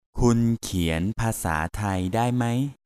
M